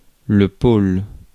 Ääntäminen
IPA: /pol/